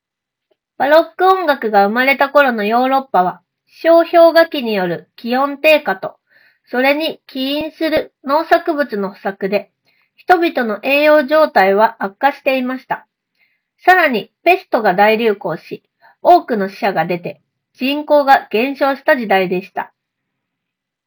ENC搭載AI通話ノイズキャンセリングに対応しており、実際に声を収録してみたところ、空調音や周囲の環境ノイズを効果的に抑えながら、装着者の声だけをしっかり拾い上げてくれました。
▼EarFun Clip 2のマイクで拾った音声単体
録音した音声を確認すると、声を鮮明に拾えており、反響感や聞き取りづらさはほとんどありません。